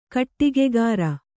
♪ kaṭṭigegāra